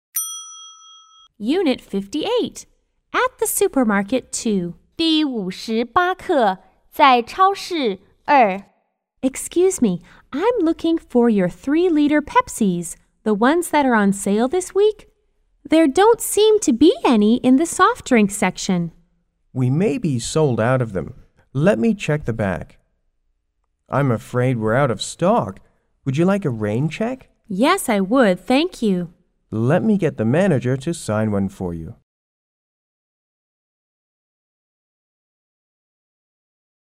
S= Shopper C= Clerk